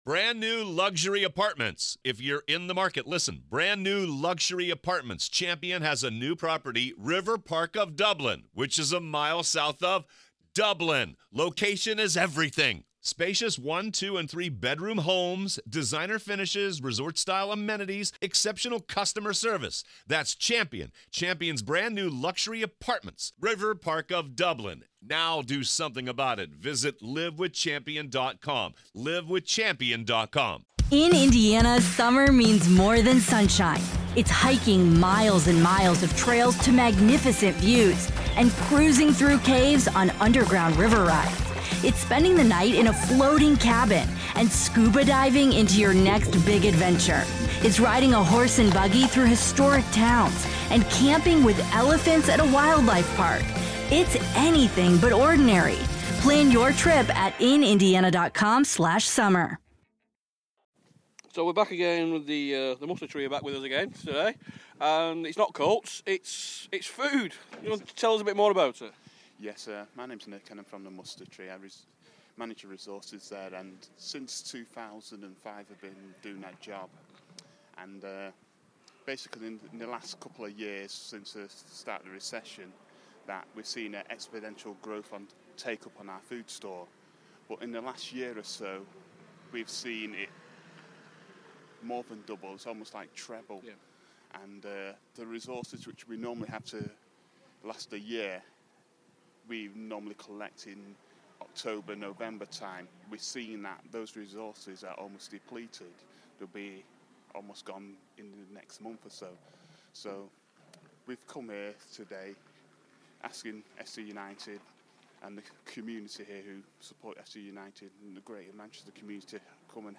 Interview
Recorded before the match against Ilkeston on the 23rd February 2013